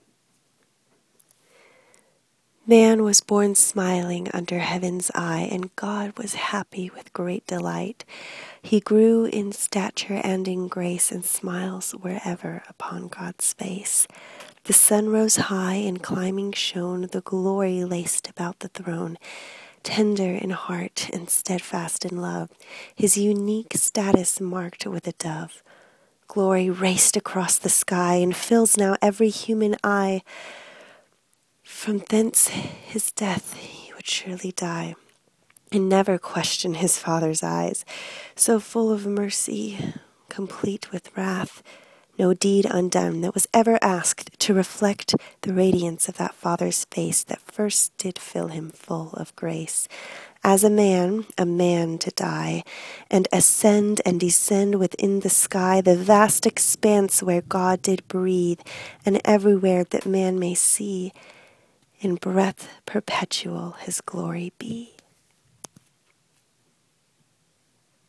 Hear me read the poem hidden in the “void.”